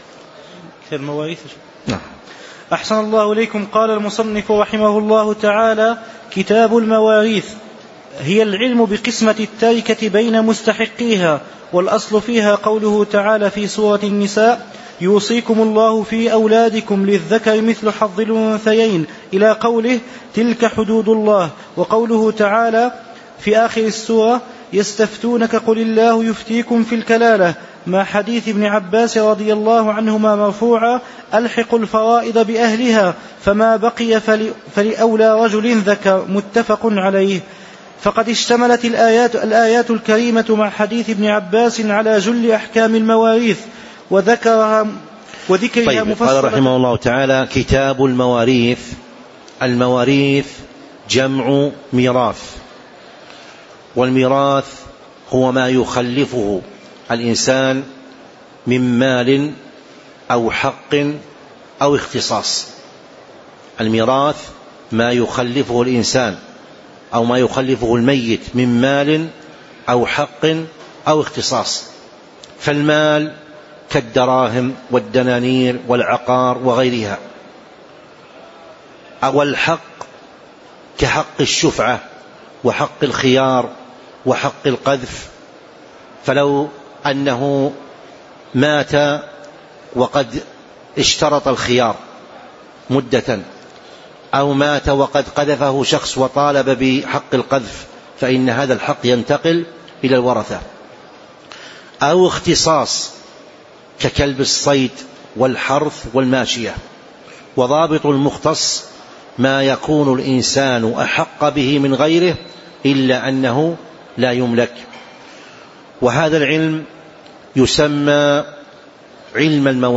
تاريخ النشر ٢٦ ربيع الثاني ١٤٤٦ هـ المكان: المسجد النبوي الشيخ